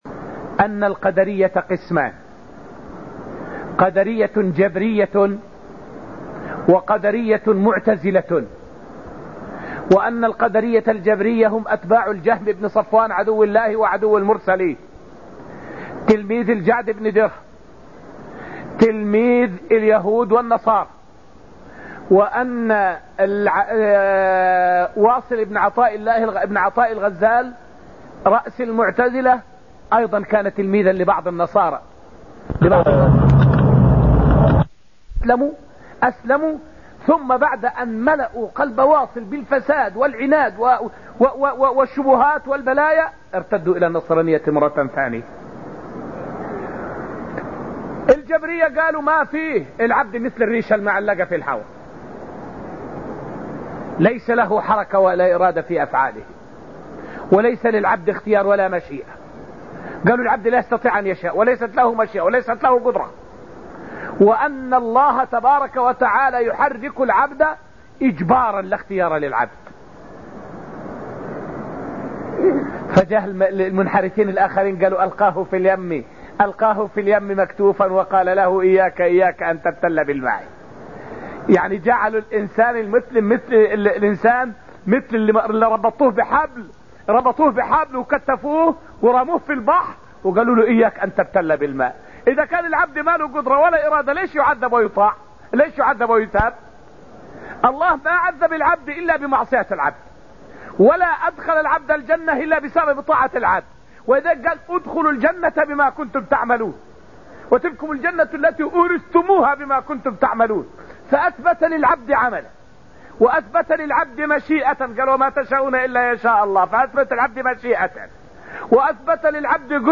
فائدة من الدرس الثاني عشر من دروس تفسير سورة القمر والتي ألقيت في المسجد النبوي الشريف حول التعامل مع وساوس الشيطان في موضوع القدر.